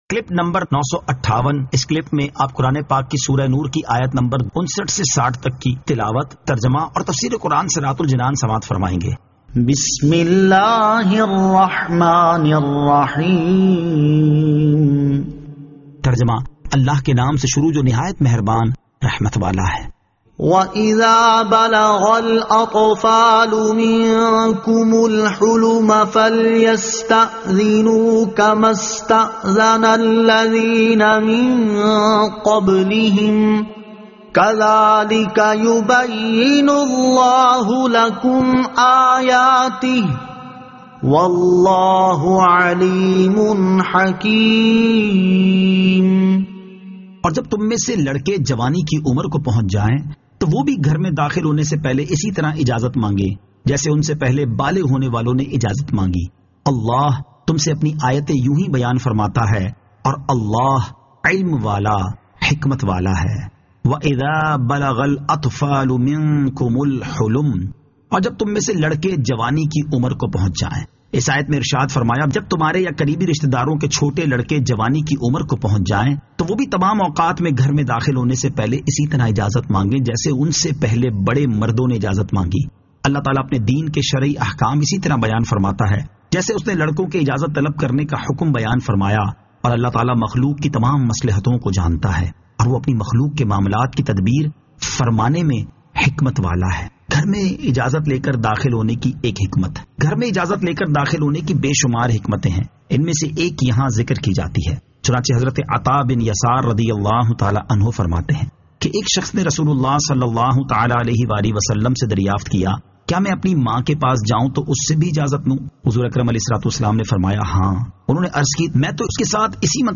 Surah An-Nur 59 To 60 Tilawat , Tarjama , Tafseer